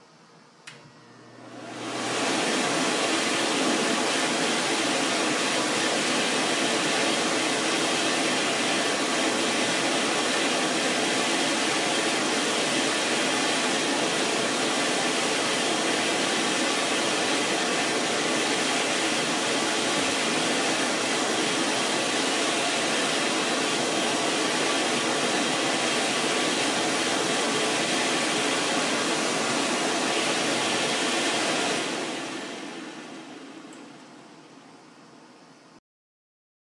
公共浴室的干手器
描述：我在公共浴室录制的浴室干手器。
Tag: 烘干机 浴室 厕所